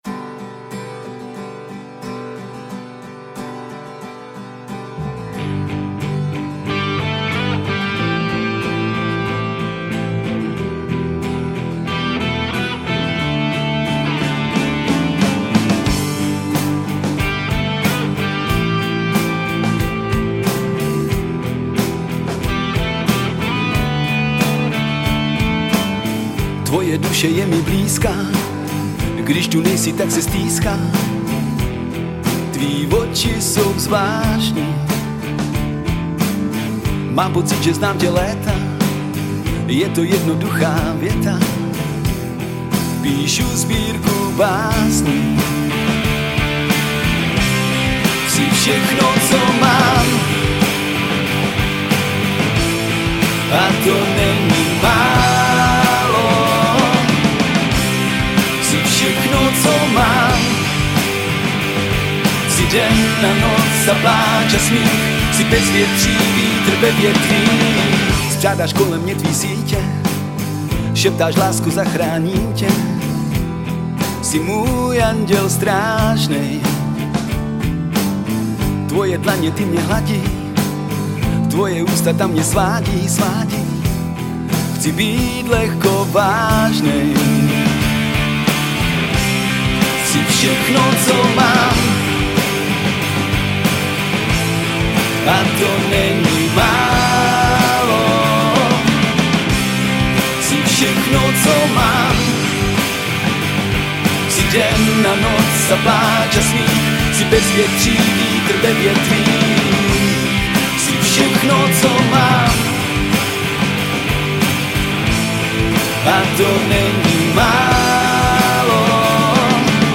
Žánr: Rock
Singl poprockové klubové kapely z Karlových Varů.